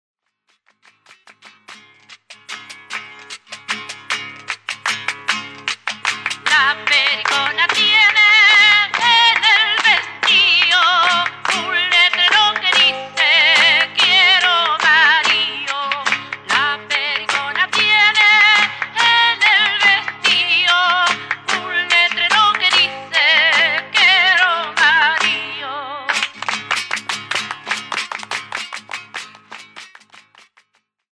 Canciones [grabación] [interpreta] Gabriela Pizarro y Conjunto.
Tipo: Cassette sonoro
Recopilación Gabriela Pizarro Interpretación Gabriela Pizarro y su conjunto
Materias: Música Folklórica - Chile